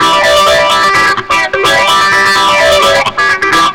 AC_GuitarB_128-A2.wav